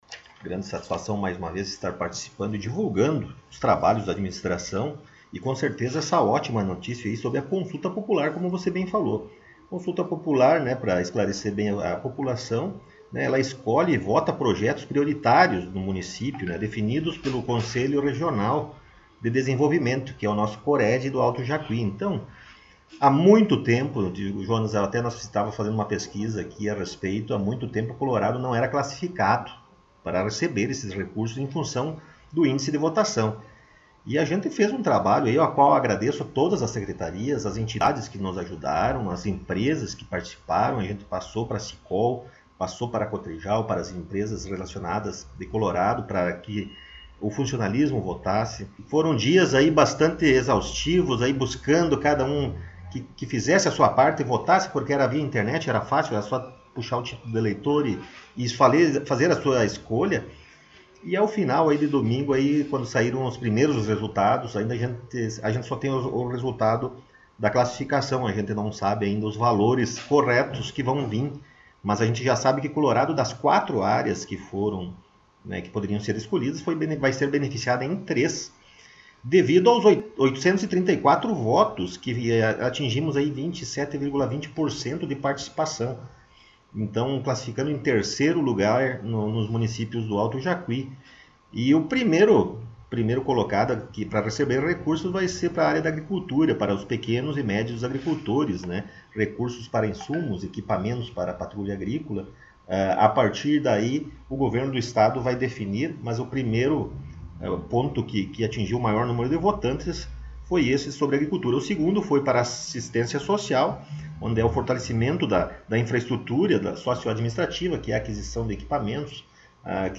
Rodrigo Sartori: Prefeito Municipal concedeu entrevista